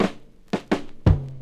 Bounce Fill.wav